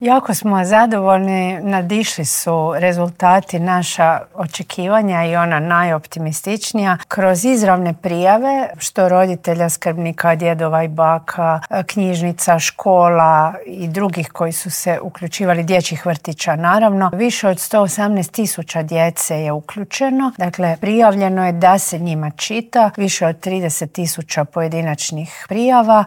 Nacionalni čitalački izazov "15 po 15 - cijela Hrvatska čita djeci" polučio je odlične rezultate, otkrila je u Intervjuu tjedna Media servisa ministrica kulture i medija Nina Obuljen Koržinek.